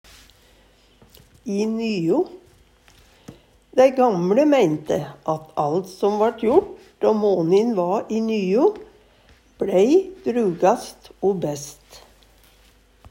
i nyo - Numedalsmål (en-US)